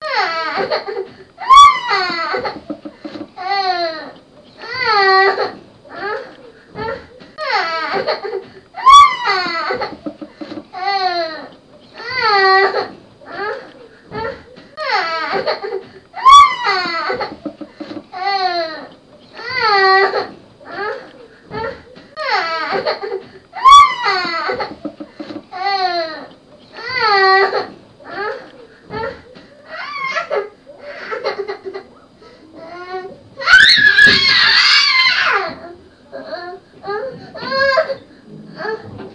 闷响的女声
描述：女性低沉的哭声。
Tag: 恐怖 害怕 情感 恐怖 哭了 hauting 困扰 悲伤 尖叫 令人毛骨悚然 困扰着 哭泣 害怕 痛苦 眼泪 哭泣 疼痛 可怕